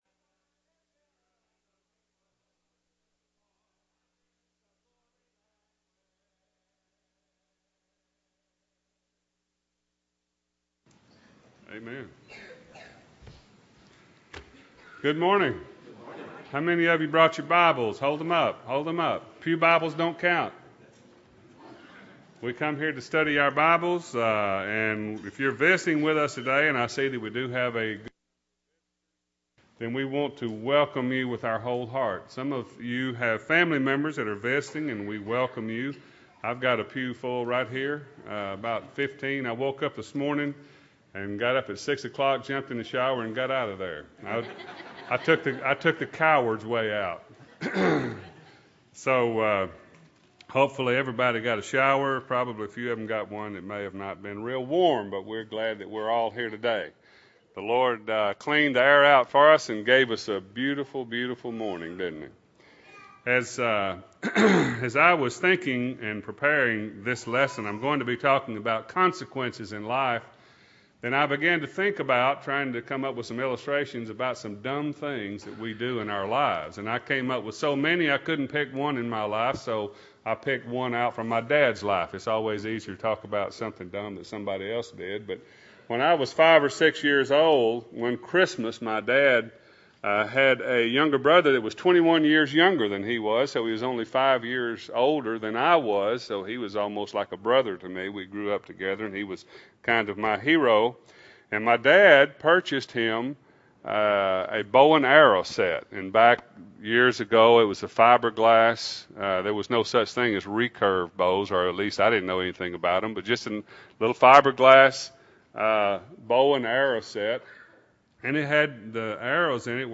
2008-02-17 – Sunday AM Sermon – Bible Lesson Recording